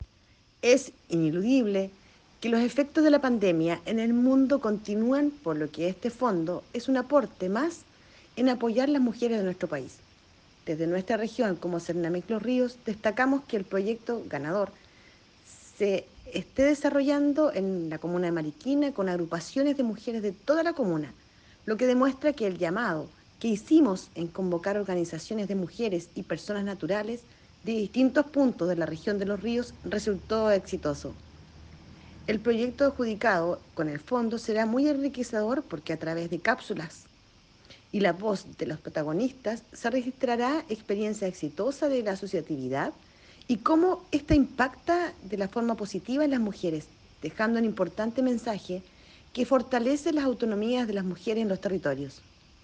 CUÑA-FEG2022-DIRECTORA-DE-SERNAMEG-WALESKA-FEHRMANN-ATERO.mp3